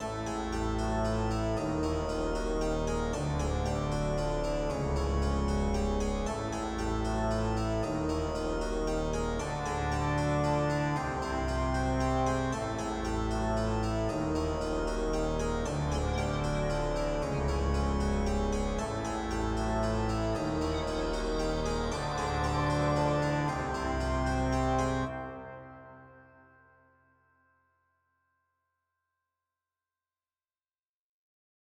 Scary Church Organ
Ambient Atmospheric Blood Cartoon Church Dizzy Drama Frankenstein sound effect free sound royalty free Memes